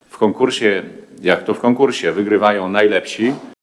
– mówi Minister Zdrowia Konstanty Radziwił.